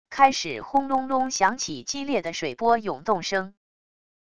开始轰隆隆响起激烈的水波涌动声wav音频